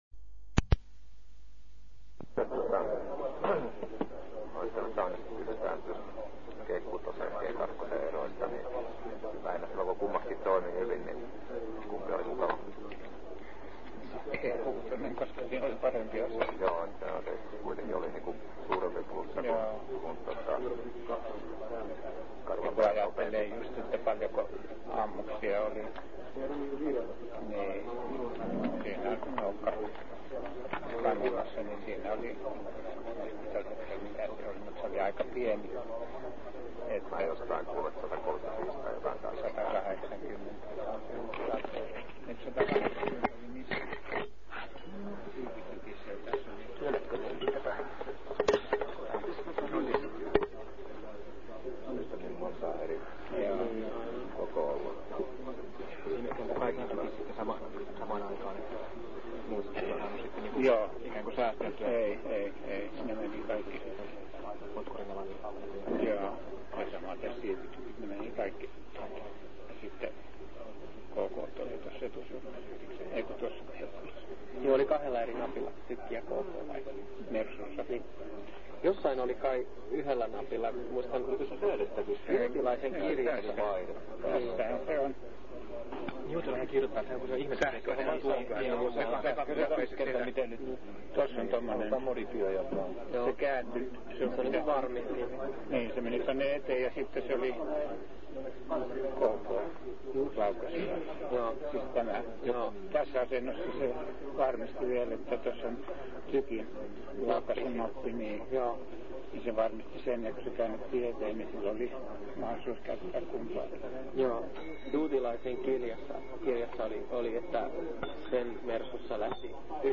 Läsnä: noin pari tusinaa henkilöä.